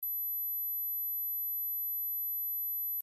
12khz- 50 and younger